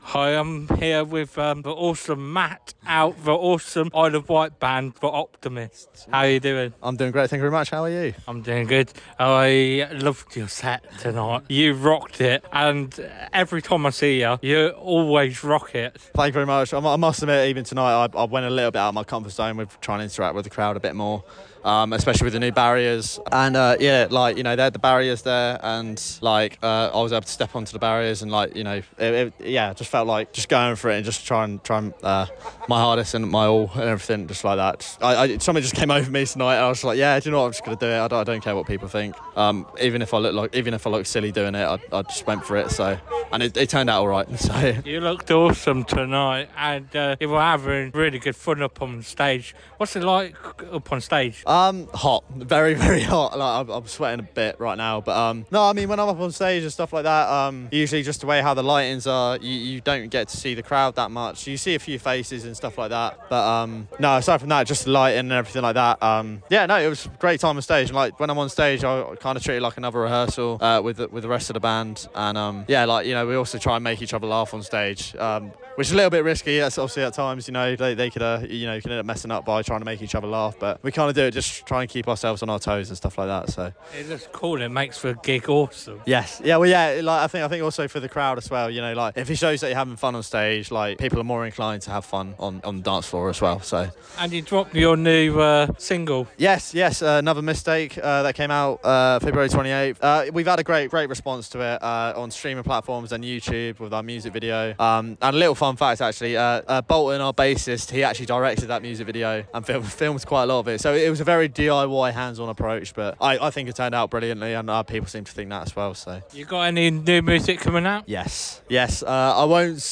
The Optimists Strings Interview 2025